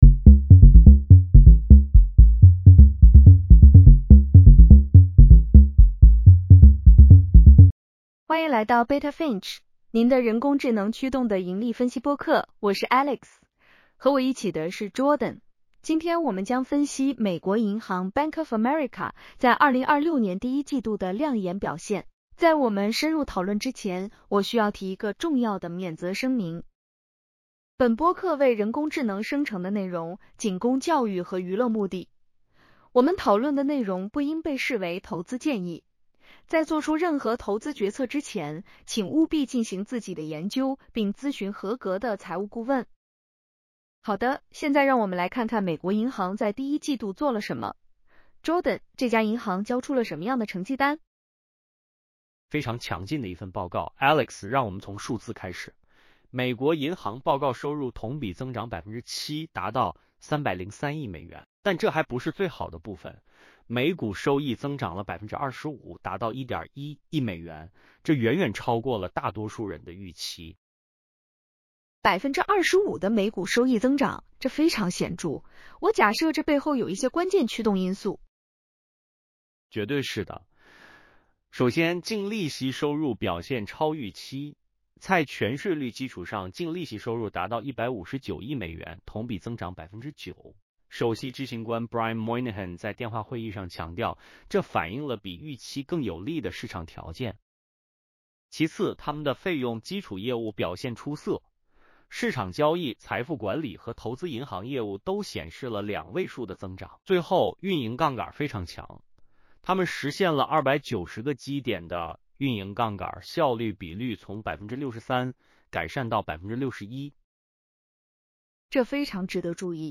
本播客为人工智能生成的内容，仅供教育和娱乐目的。